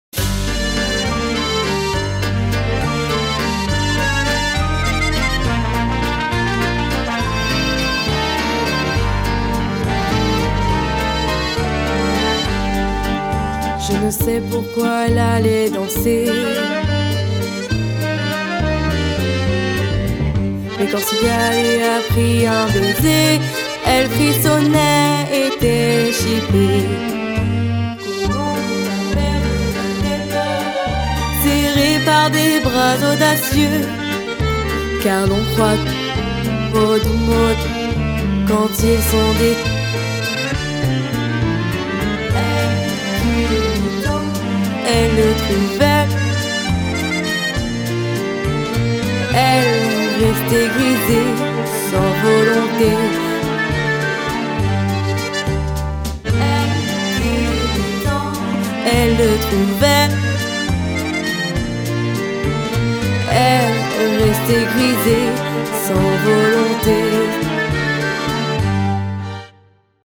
La version chantée avec les trous (Facile)